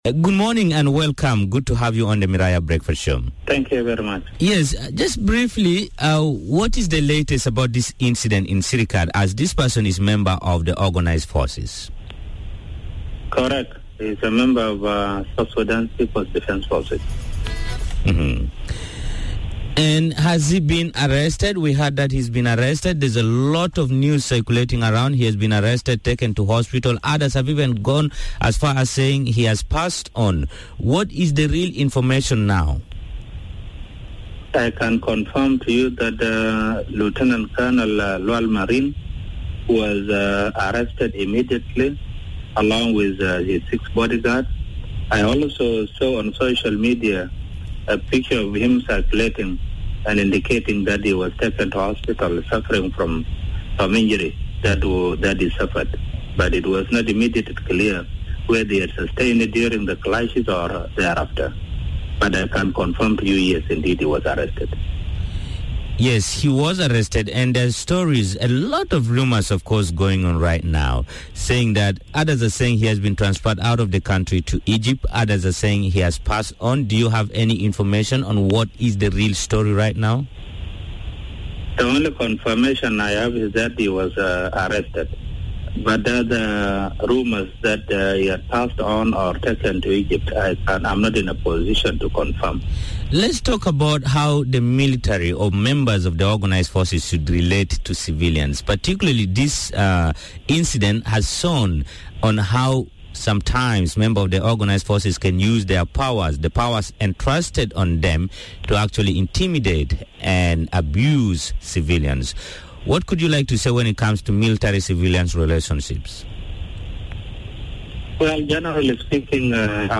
Gen Koang says rules and regulations require the army to maintain cordial relations with civilians. He spoke to Miraya breakfast this morning.